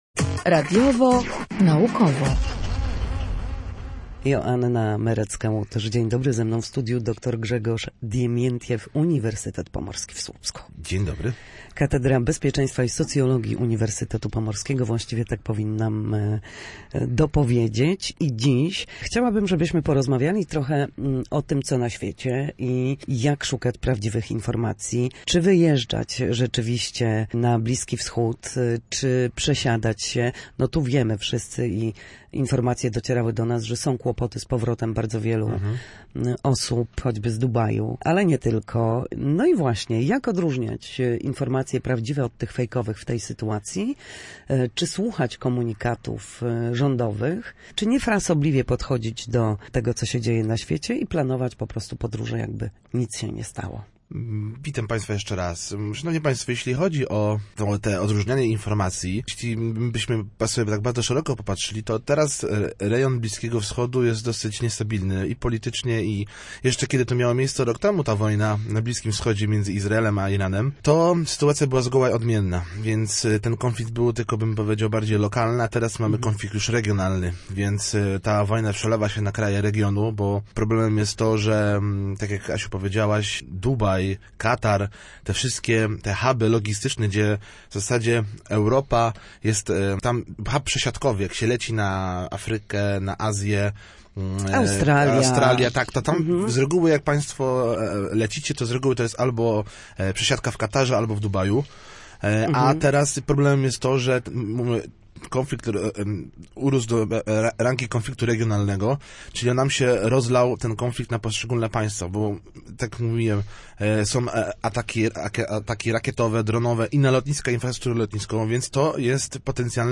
Na naszej antenie mówił o tym, jak radzić sobie z dezinformacją, postami czy filmami generowanymi przy wsparciu sztucznej inteligencji oraz jak weryfikować prawdę.